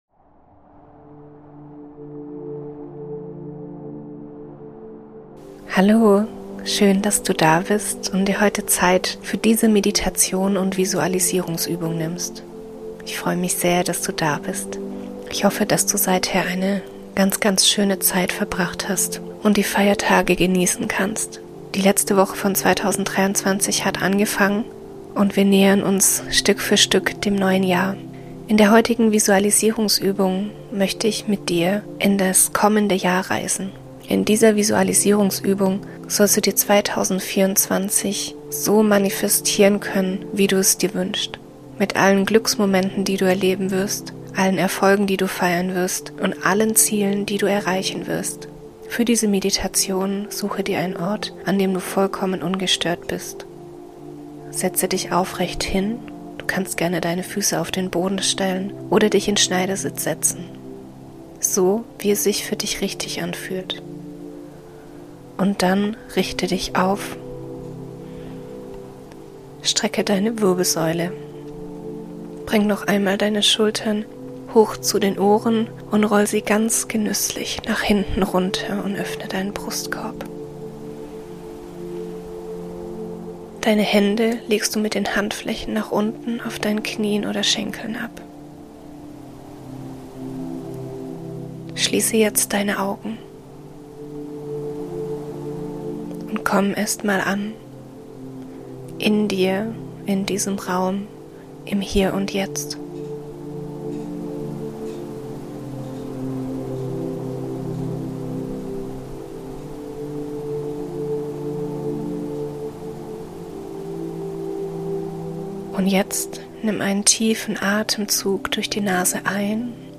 In der heutigen Meditation wartet eine Visualisierungsübung für das Jahr 2024 auf dich. Du wirst in das kommende Jahr reisen und dir deine Ziele, Wünsche und Träume manifestieren können.